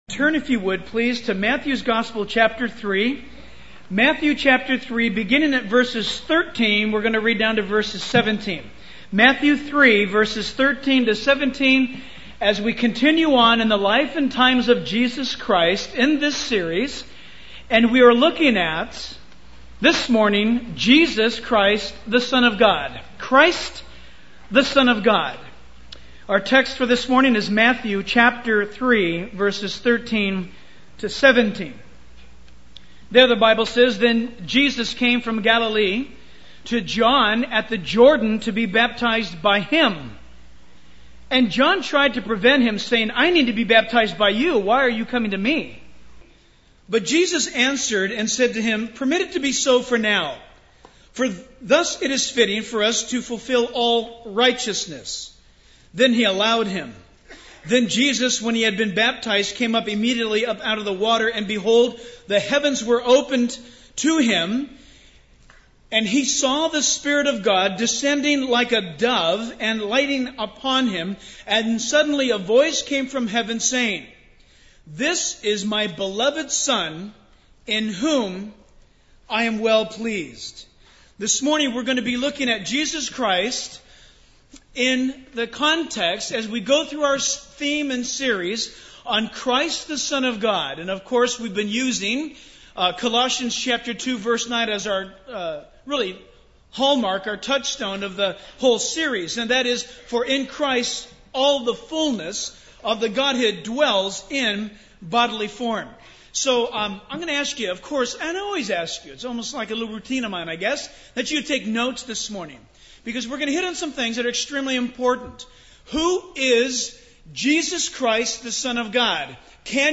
In this sermon, the speaker emphasizes the importance of coming to God and trusting in Him.